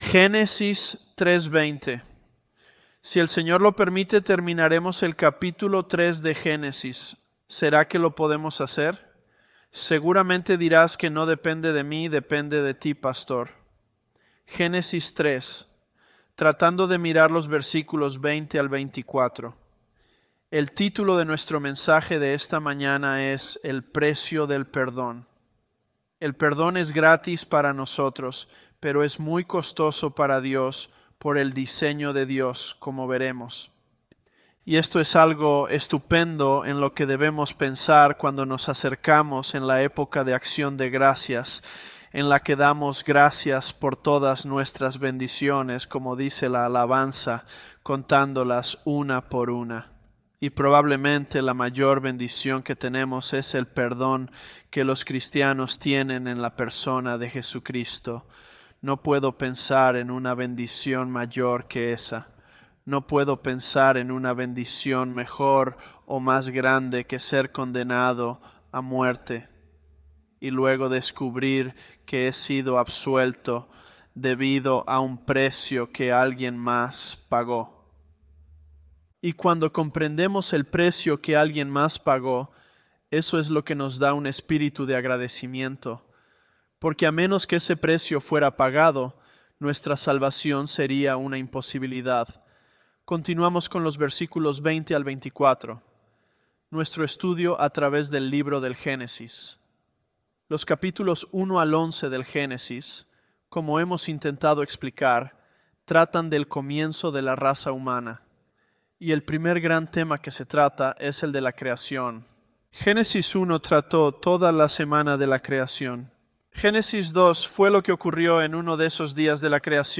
ElevenLabs_Genesis-Spanish015.mp3